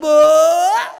SCREAM 2.wav